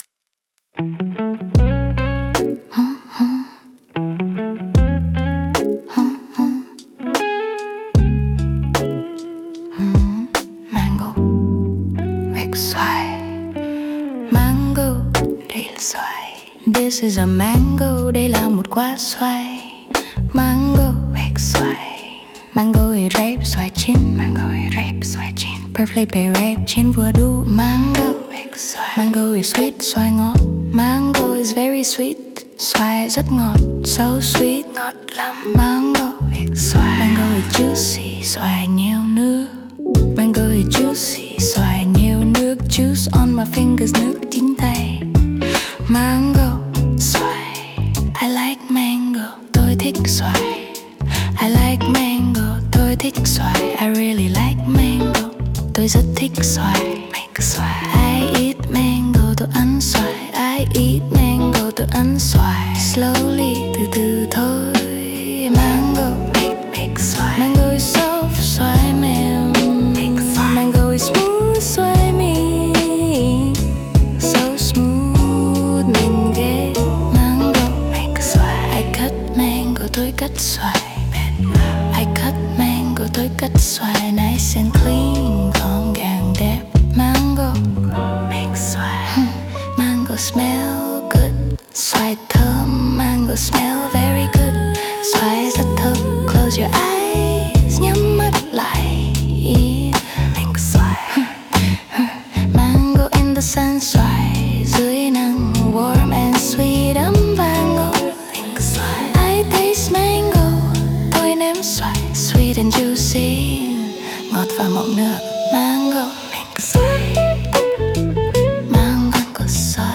Learn about Vietnamese Mango in a Sensual Song